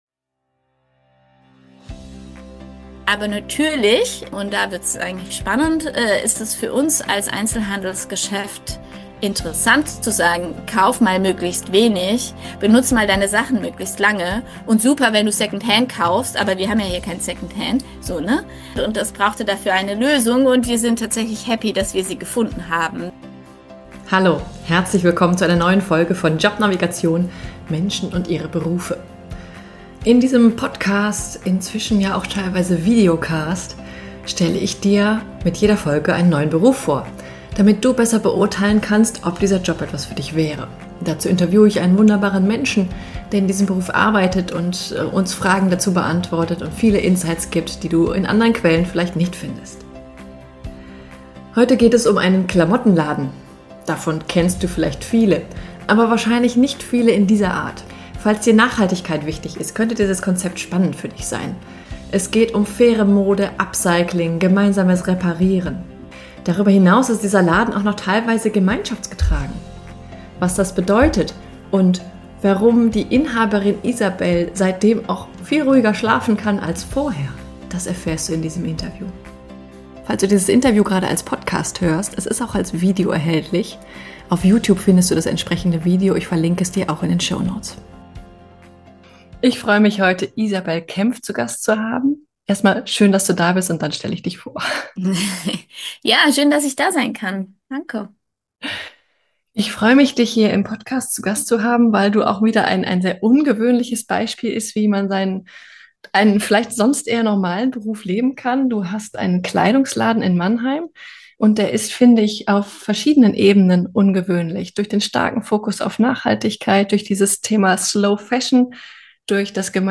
Was das bedeutet, erfährst du im Interview.